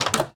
Minecraft Version Minecraft Version 1.21.5 Latest Release | Latest Snapshot 1.21.5 / assets / minecraft / sounds / block / wooden_door / open1.ogg Compare With Compare With Latest Release | Latest Snapshot